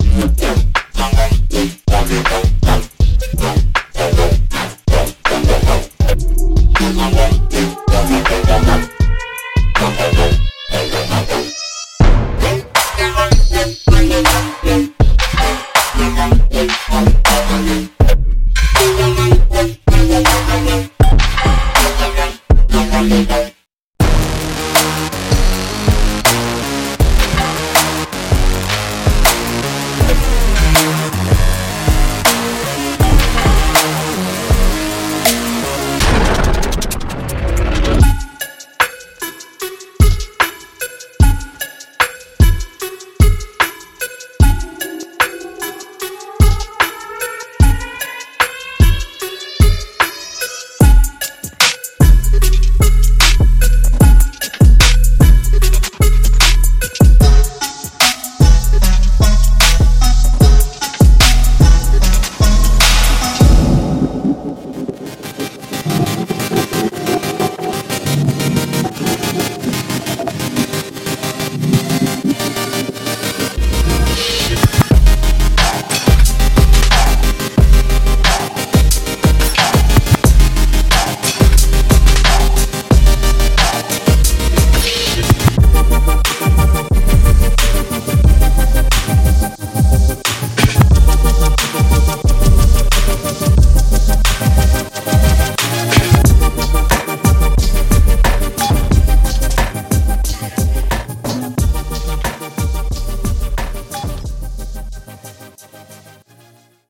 受制于陷阱，半场和左场电子节拍的启发
以实验性的原始声音推动信封
可以通过美丽的淡谷前置放大器来达到这种深度。